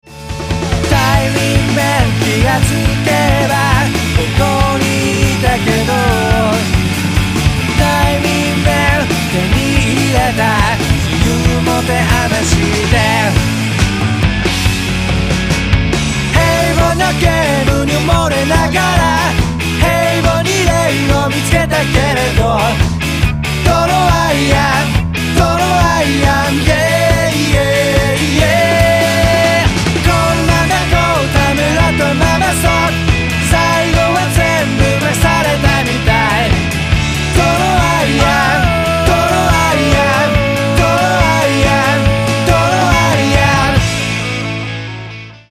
どこまでもエヴァー・グリーンなパワー・ポップ！！！！